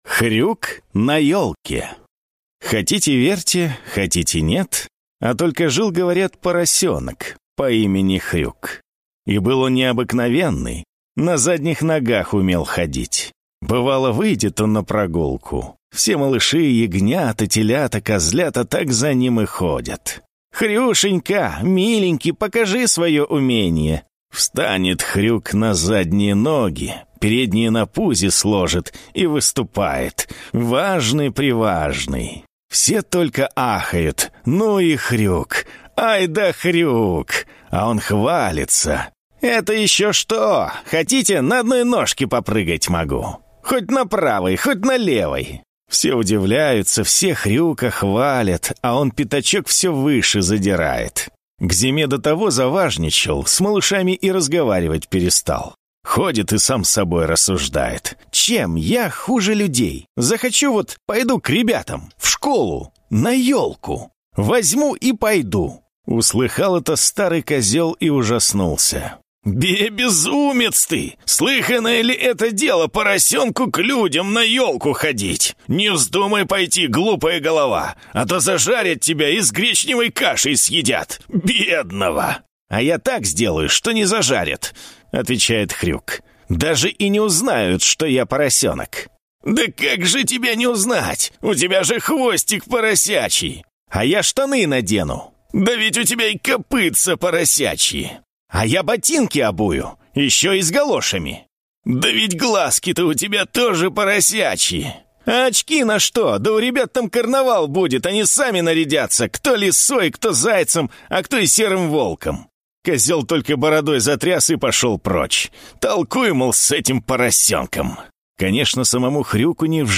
На данной странице вы можете слушать онлайн бесплатно и скачать аудиокнигу "Хрюк на ёлке" писателя Борис Заходер.